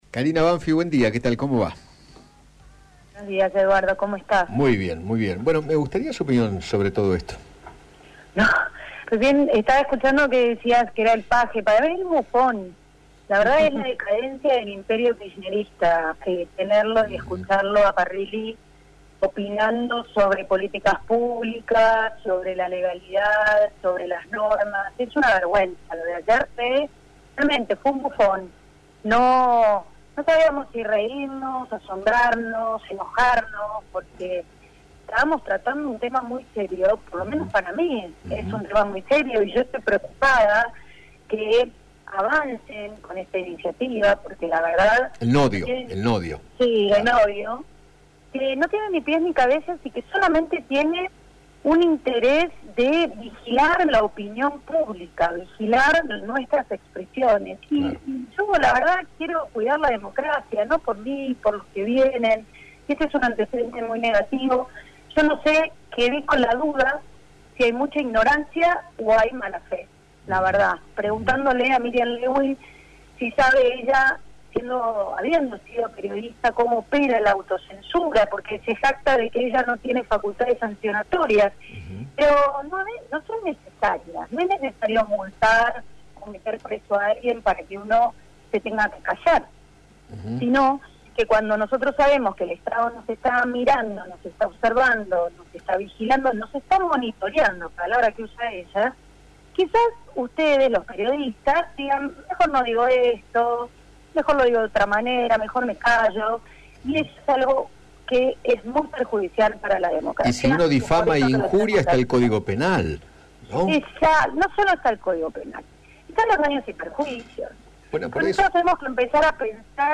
Karina Banfi, Diputada Nacional por la provincia de Buenos Aires, dialogó con Eduardo Feinmann sobre el organismo creado para supervisar lo que divulgan los medios de comunicación y sostuvo que  “los primeros difundidores de noticias falsas son los funcionarios, ¿cómo van a hacer con eso?”.